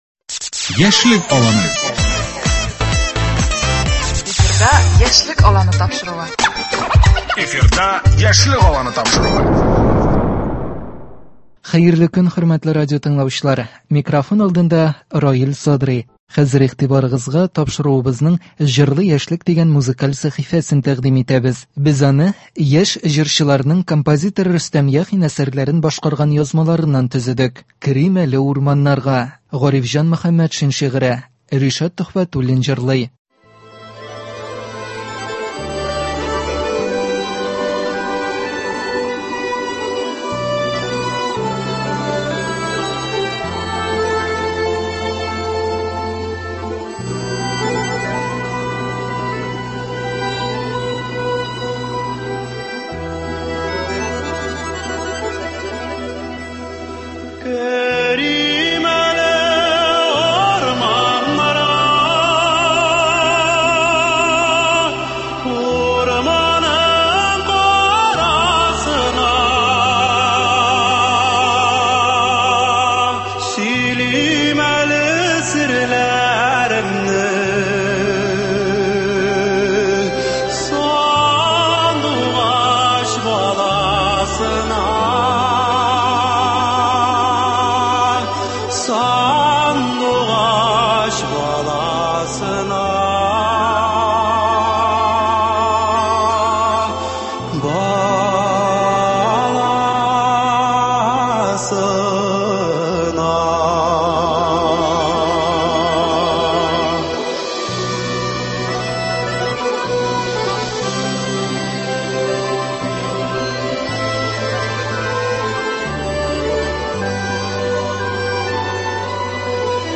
Яшь башкаручыларның яңа язмалары.